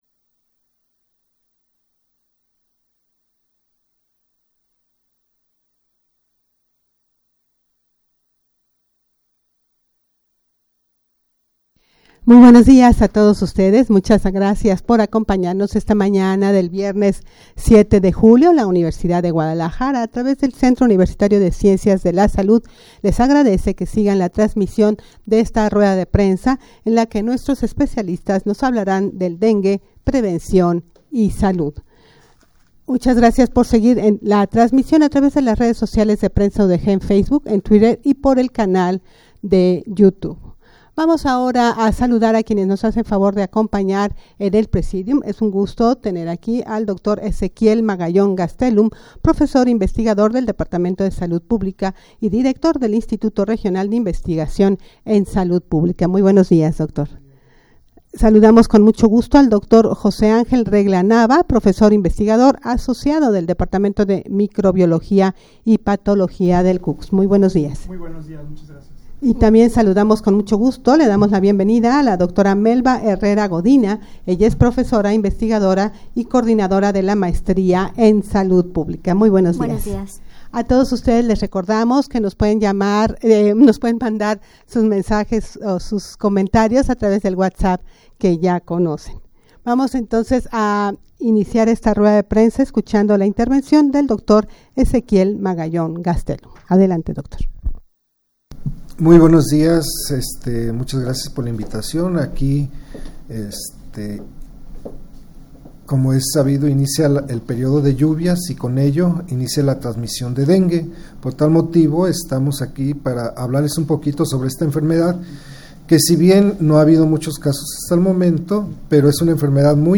rueda-de-prensa-sobre-dengue-prevencion-y-salud.mp3